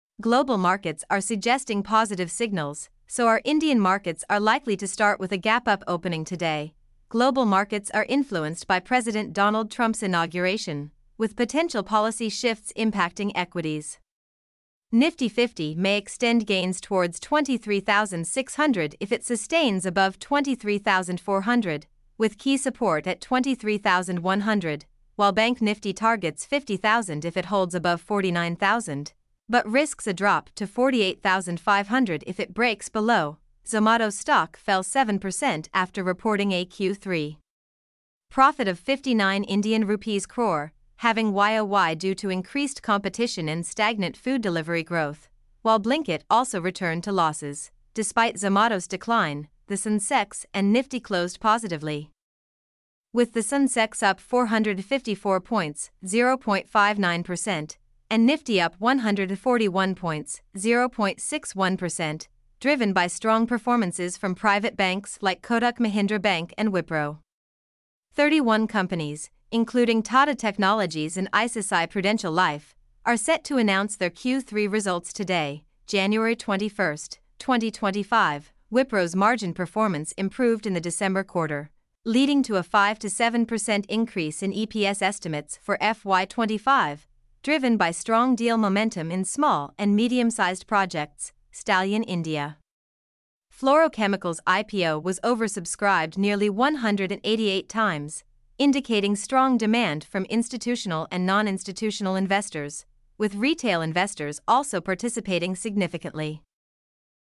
mp3-output-ttsfreedotcom-2.mp3